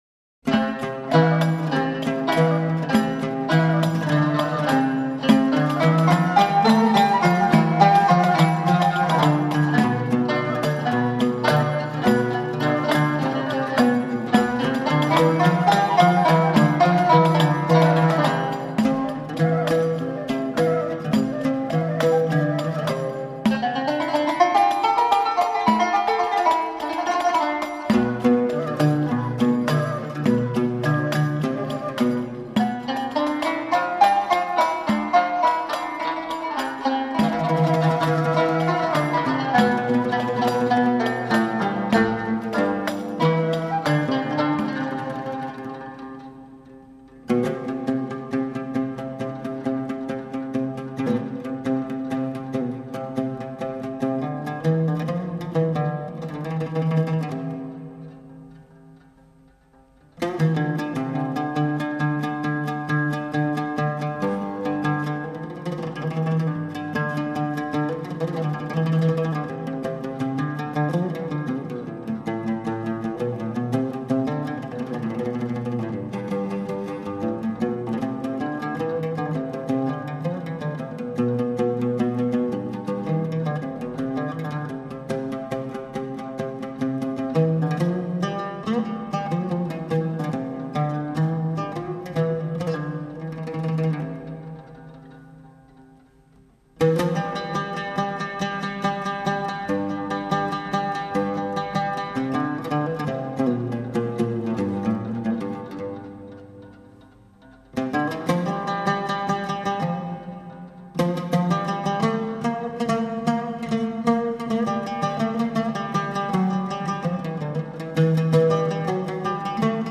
مقام راست